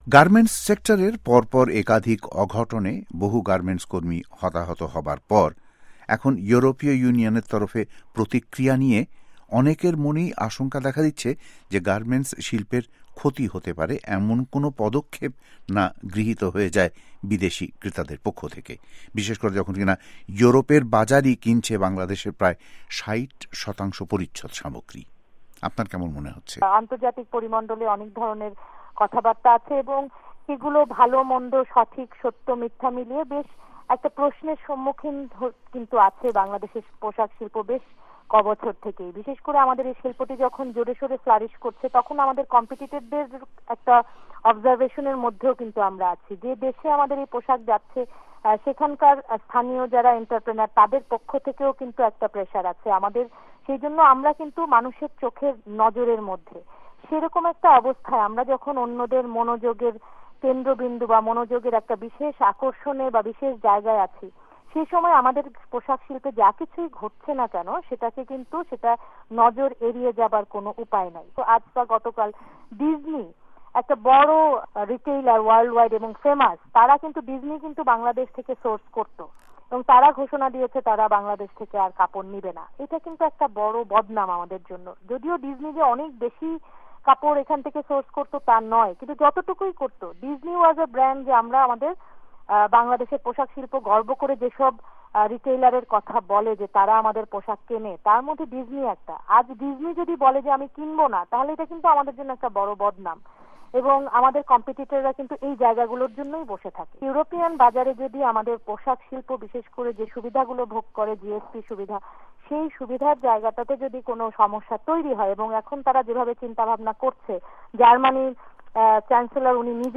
ওয়াশিংটন স্টুডিও থেকে তাঁর সঙ্গে কথা বলেন